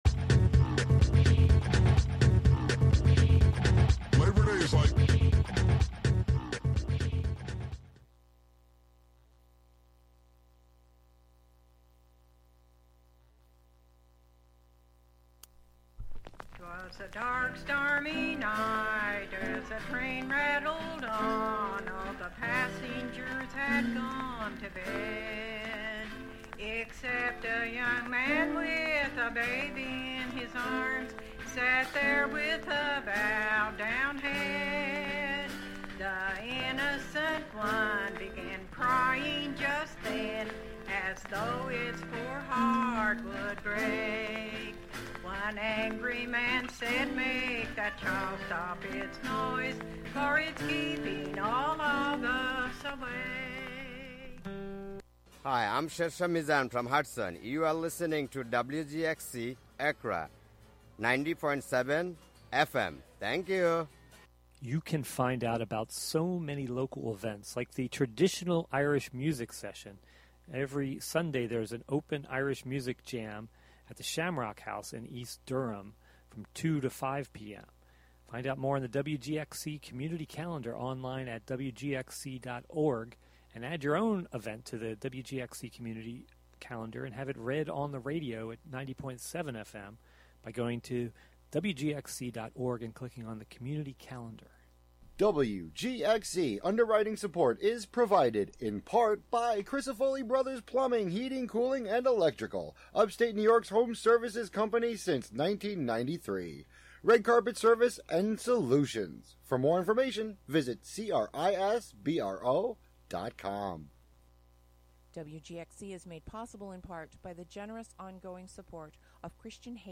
Tune in to this quarterly broadcast composed of local sound ecology, observational narration, and articulations of the mechanical components of the M49; its meanings and purpose reshaped as it traversed the machine of the road to arrive at its site in the forest of Wave Farm.